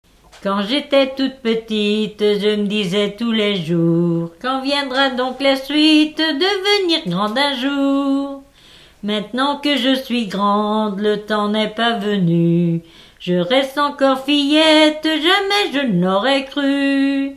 Mémoires et Patrimoines vivants - RaddO est une base de données d'archives iconographiques et sonores.
Genre strophique
Catégorie Pièce musicale inédite